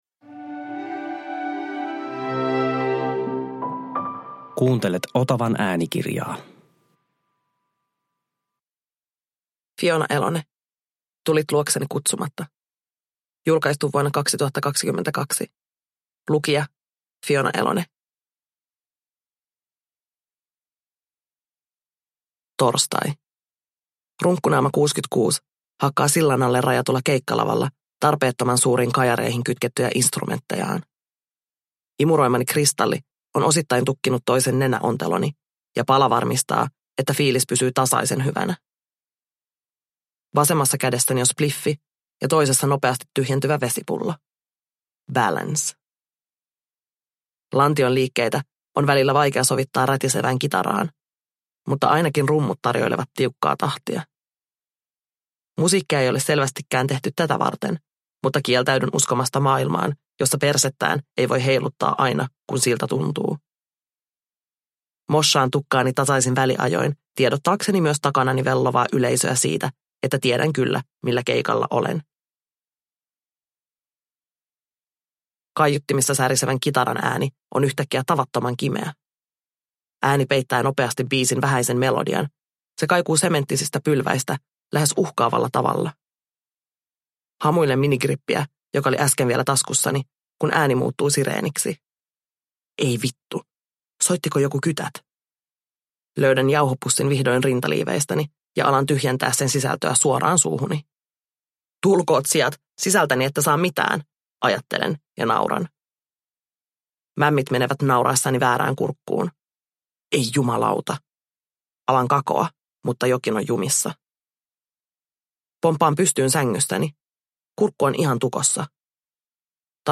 Tulit luokseni kutsumatta – Ljudbok – Laddas ner